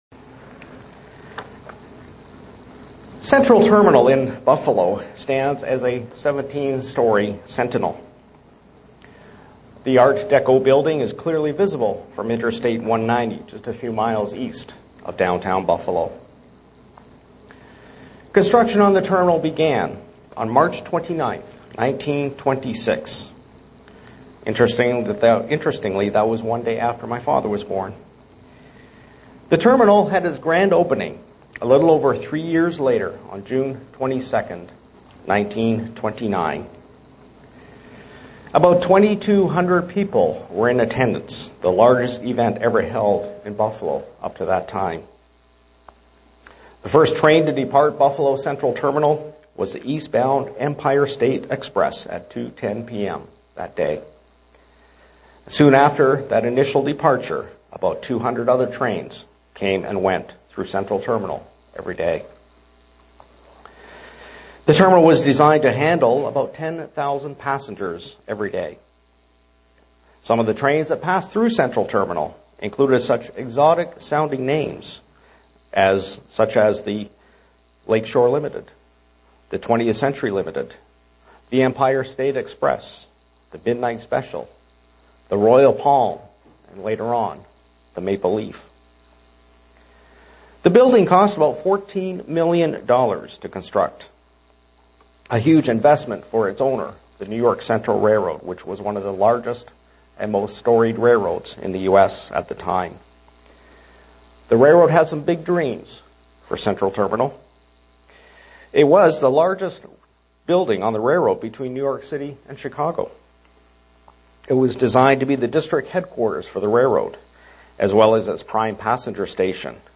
SEE VIDEO BELOW UCG Sermon Studying the bible?
Given in Buffalo, NY